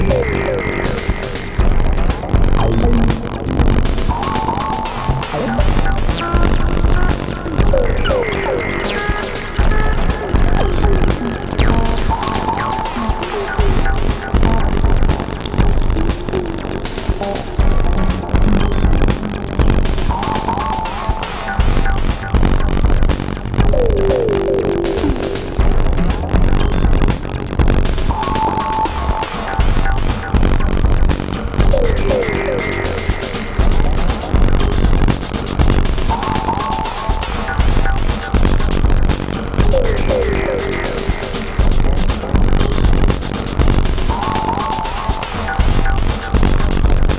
So yeah, the issue starts here, but by the end of the recording it is constant and LOUD.
Maybe I should post some of my music that doesn’t get all wackadoo.